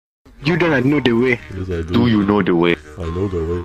uganda-knuckles-do-you-know-the-way-redux-version.mp3